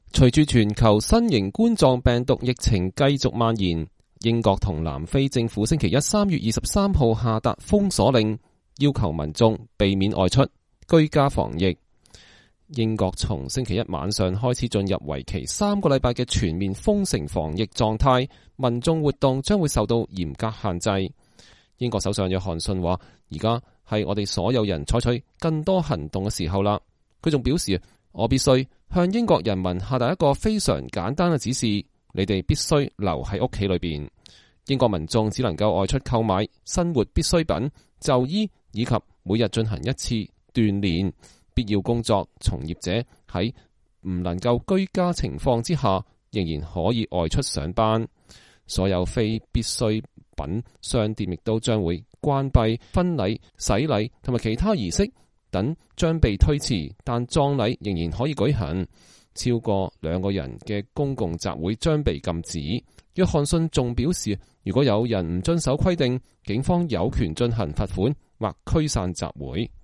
英國首相鮑里斯·約翰遜宣布英國將進入全國封城防疫狀態。